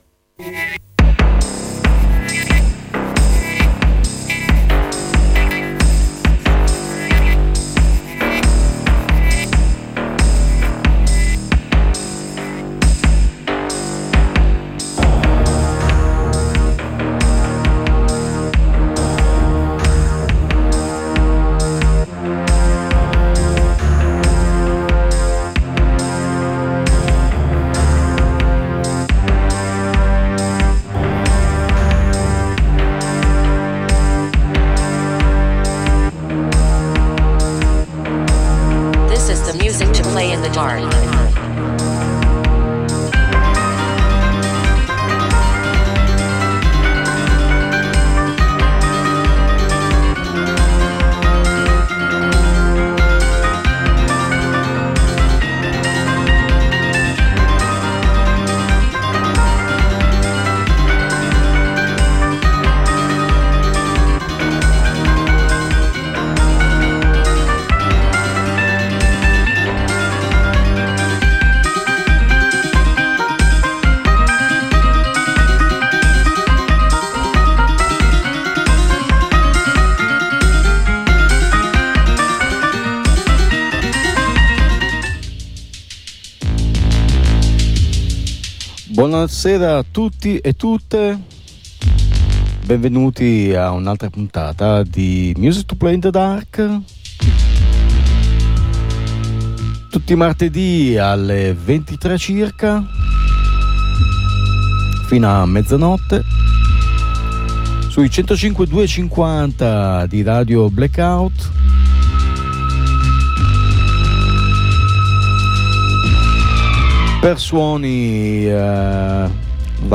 Per un’ora verrete condotti attraverso un percorso trasversale fatto da sonorità che non si fermano ad un genere: si può passare dall’industrial alla wave, facendo una fermata nel punk, nel death metal, nell’electro oppure anche nel math rock. Seguiremo le storie di chi ha fatto dei suoni non convenzionali l’espressione della propria persona con ascolti ed alle volte con interviste.